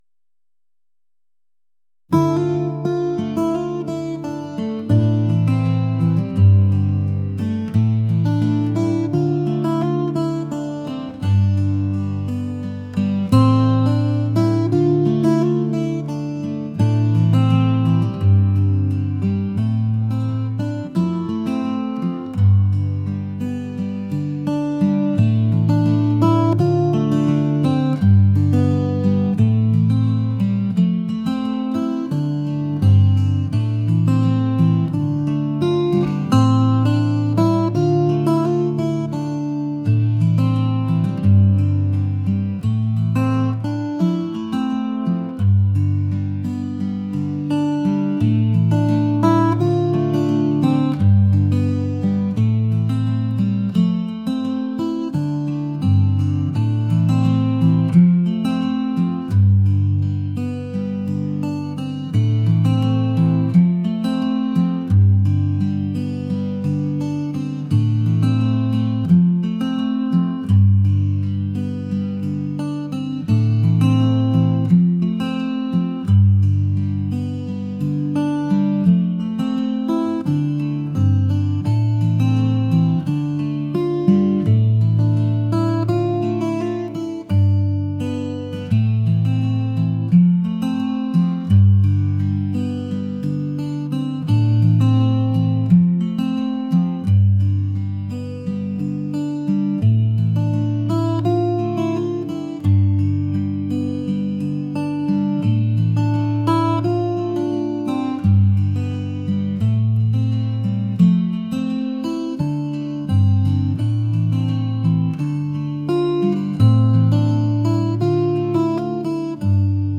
folk | acoustic